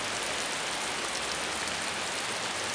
lluvia.mp3